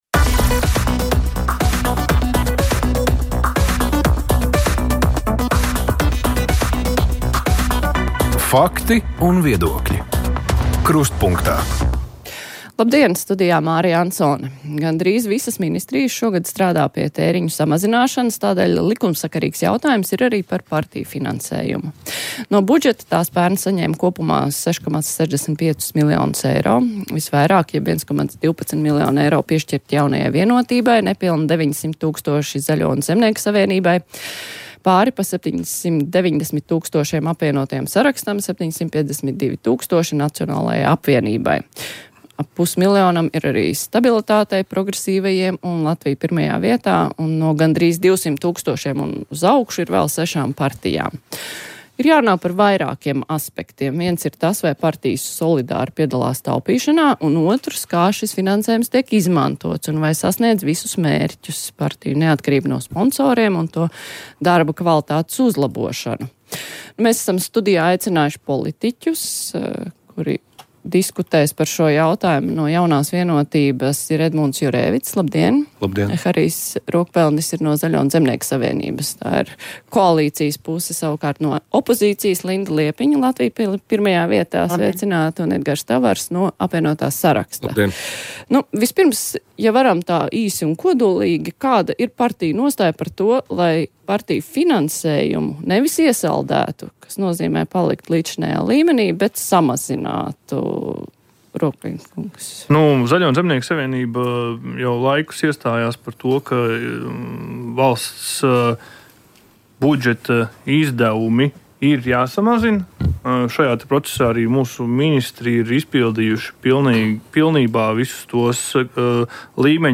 Studijā Saeimas sociālo un darba lietu komisijas Nevienlīdzības mazināšanas apakškomisijas priekšsēdētāja Zane Skujiņa-Rubene, Saeimas budžeta un nodokļu komisijas priekšsēdētājas vietnieks Andis Šuvajevs, Saeimas frakcijas Nacionālās apvienības priekšsēdētāja vietniece Ilze Indriksone un Saeimas aizsardzības, iekšlietu un korupcijas novēršanas komisijas deputāts Edmuds Zivtiņš.